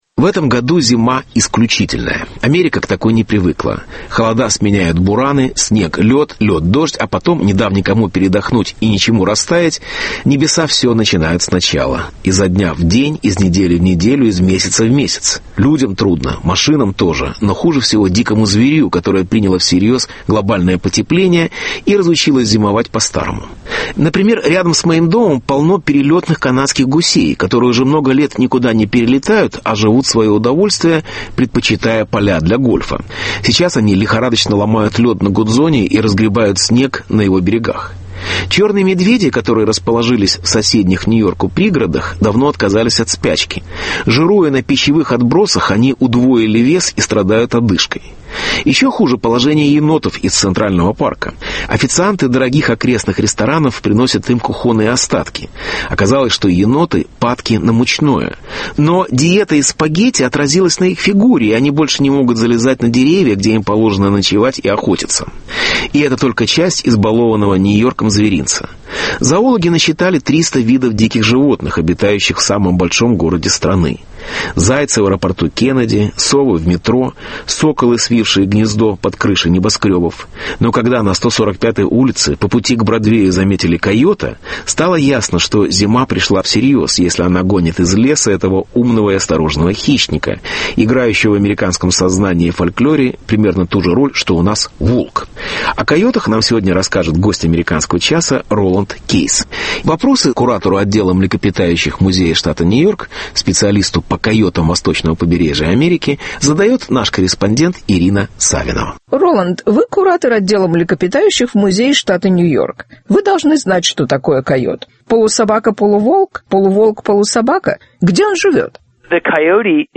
Гость АЧ – зоолог